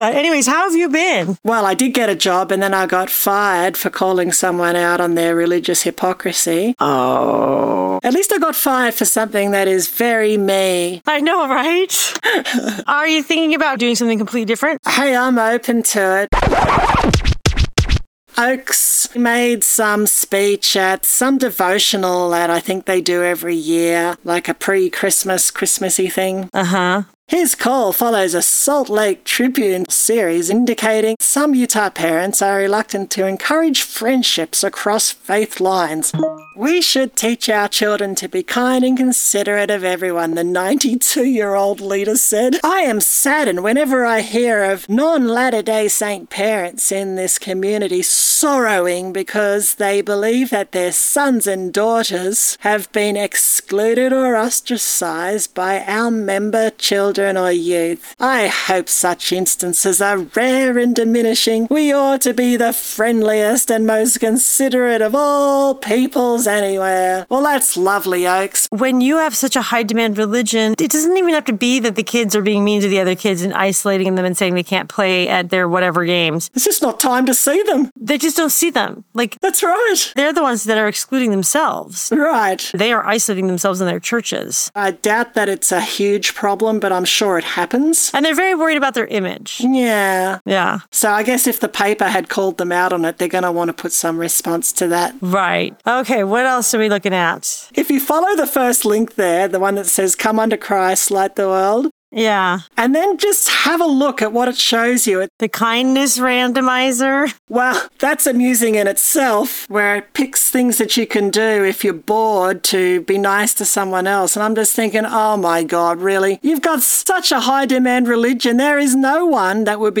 Reading & Roasting the Book of Mormon, cover-to-cover.